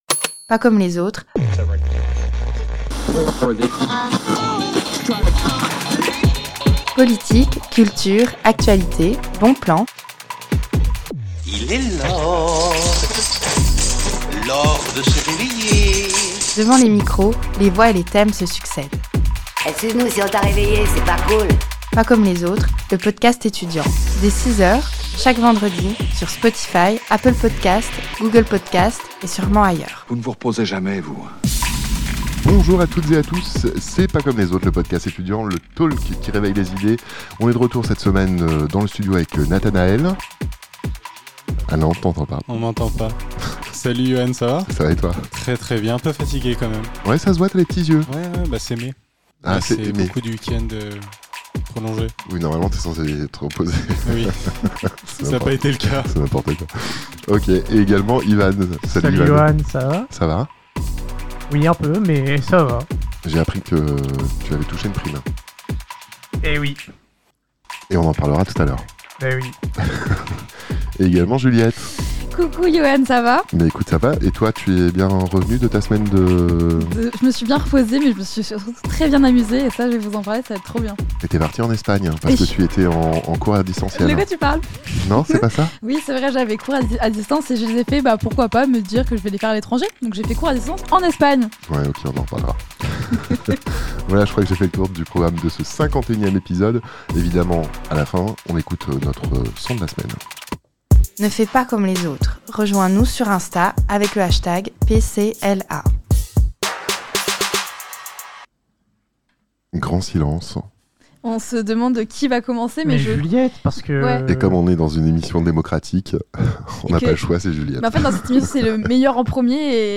Mais on rigole bien ????
A la fin de l'épisode, on écoute notre son de la semaine.